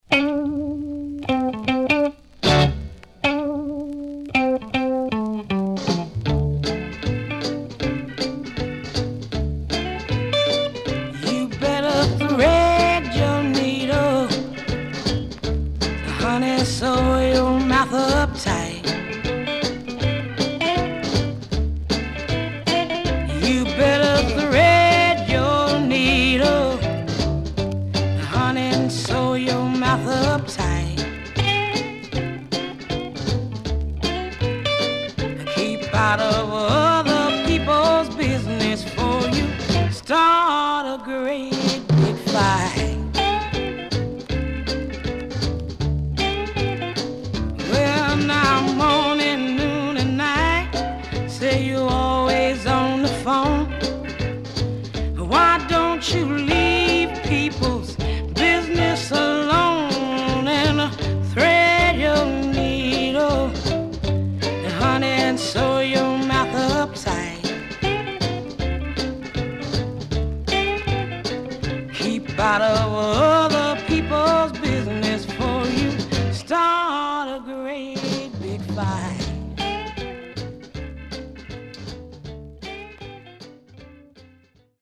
男女R&B Duo
SIDE A:所々チリノイズ入ります。